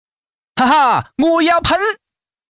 Index of /hunan_master/update/12815/res/sfx/changsha_man/